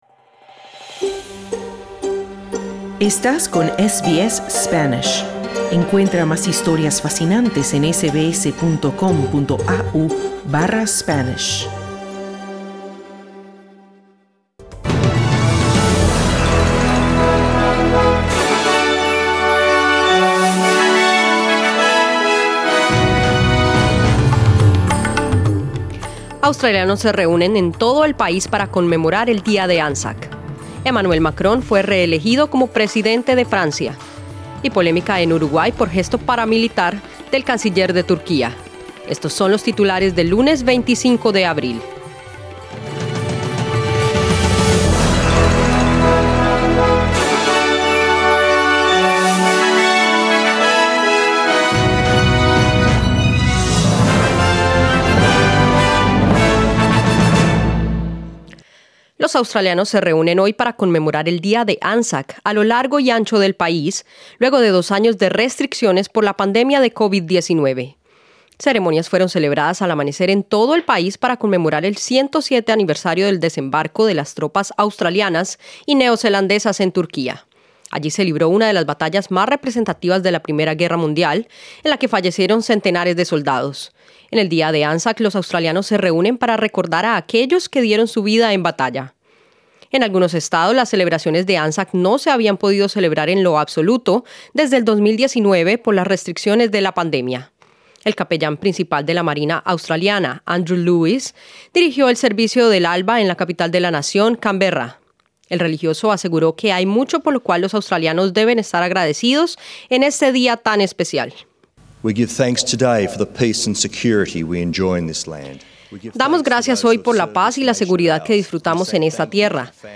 Noticias SBS Spanish | 25 Abril 2022